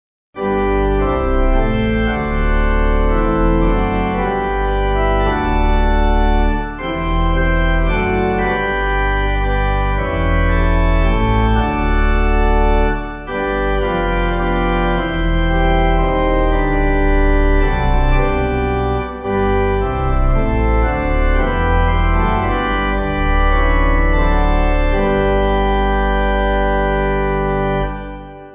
Organ
(CM)   7/G